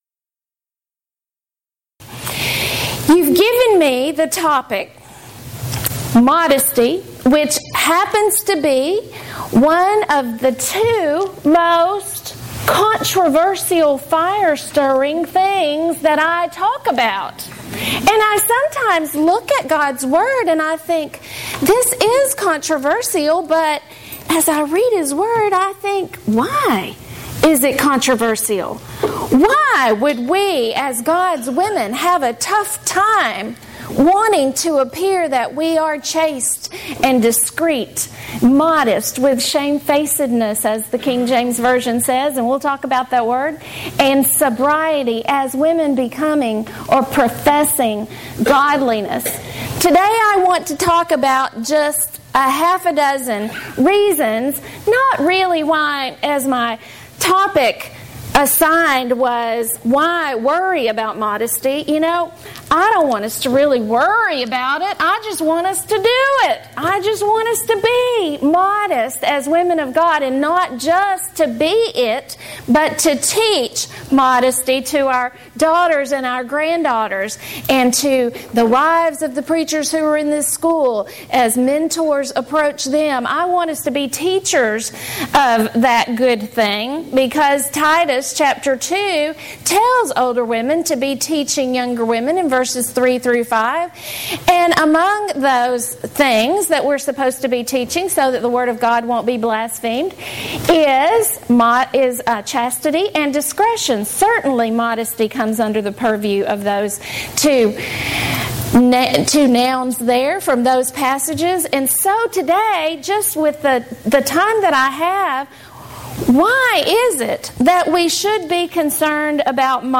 Alternate File Link File Details: Series: Southwest Lectures Event: 32nd Annual Southwest Lectures Theme/Title: Why Do We...
lecture